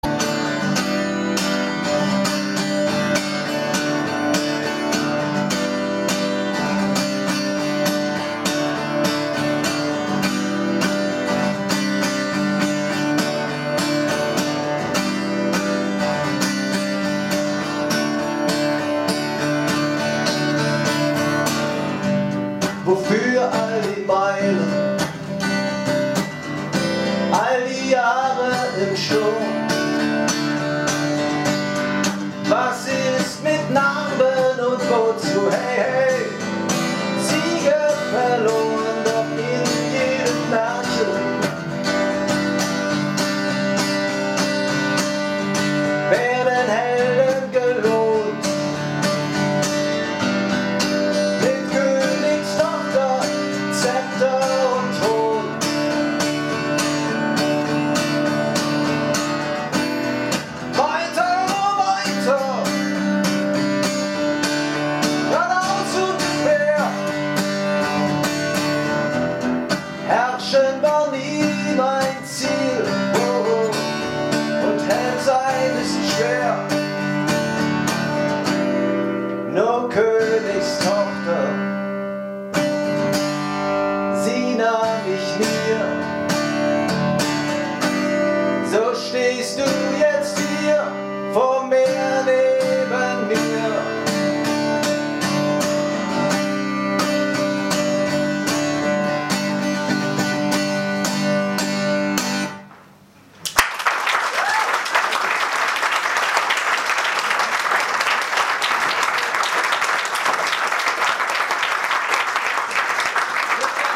2014 in Frankfurt/ Oder